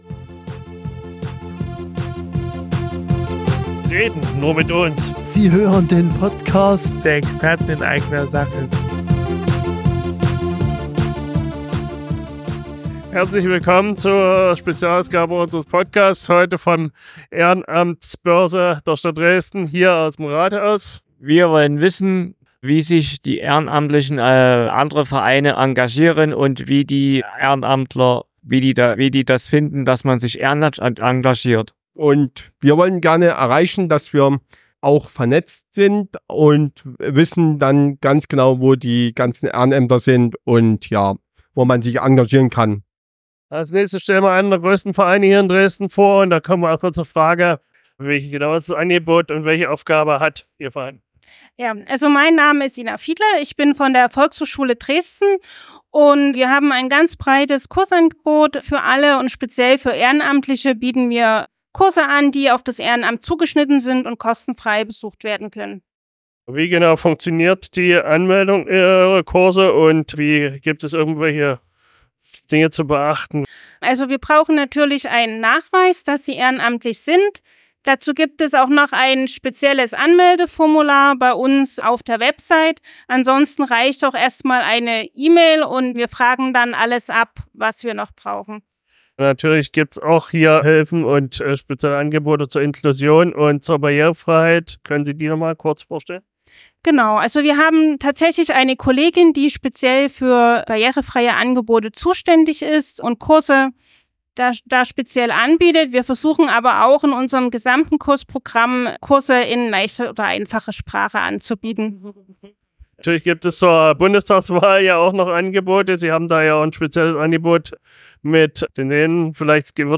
Beschreibung vor 1 Jahr Am 27.12. waren wir bei der Ehrenamtsbörse im Dresdner Rathaus. Dort haben wir für unseren Podcast mit vielen Menschen und Vereinen gesprochen, die Hilfe brauchen.
In dieser Folge stellen sich die Vereine vor. Wir haben auch mit Oberbürgermeister Dirk Hilbert gesprochen.